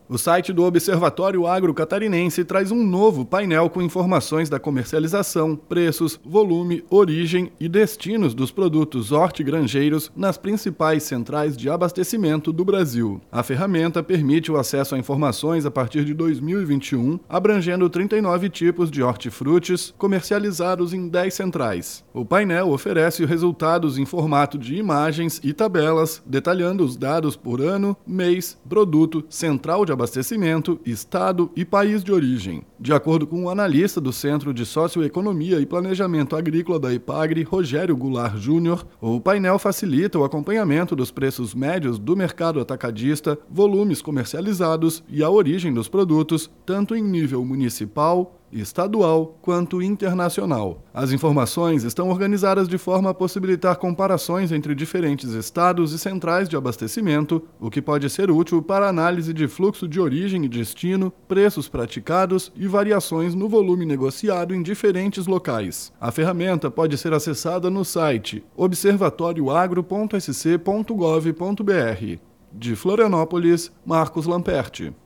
BOLETIM – Observatório Agro Catarinense tem novo painel sobre o mercado de hortifrútis
Repórter